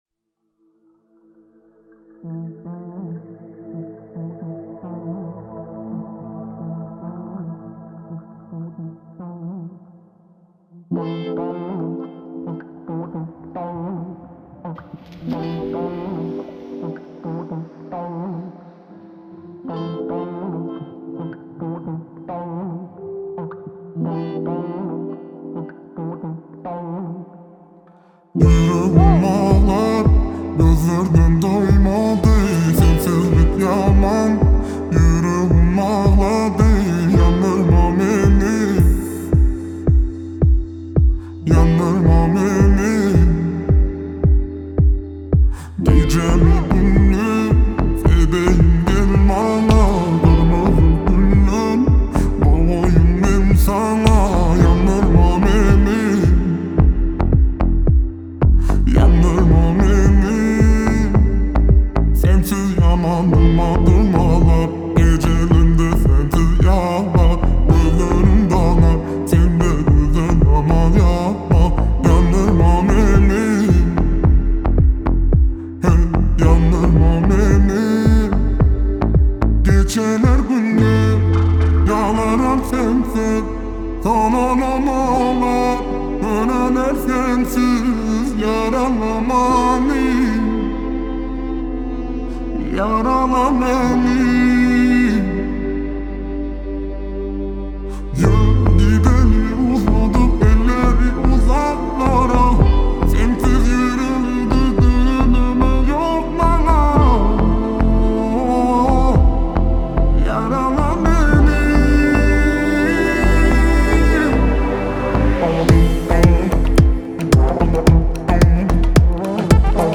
آهنگ ترکیه ای معروف